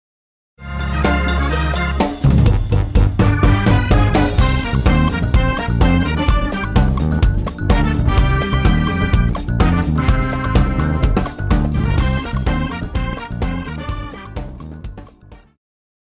爵士
電吉他
樂團
演奏曲
放克
獨奏與伴奏
有主奏
有節拍器
a funky, old-school guitar solo on the Gibson ES 335